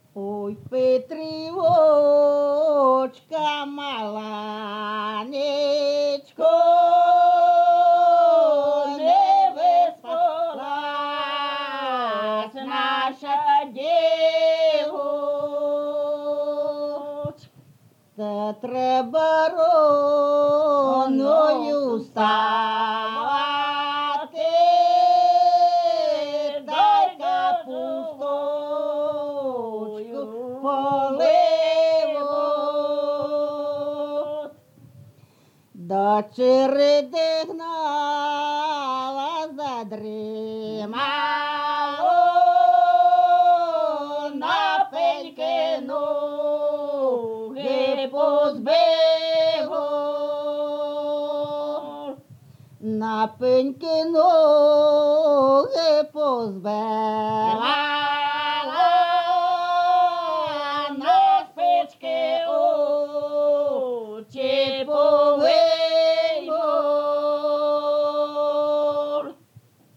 ЖанрПетрівчані
Місце записус. Будне, Охтирський район, Сумська обл., Україна, Слобожанщина